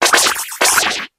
Grito de Arrokuda.ogg
) Categoría:Gritos de Pokémon de la octava generación Categoría:Arrokuda No puedes sobrescribir este archivo.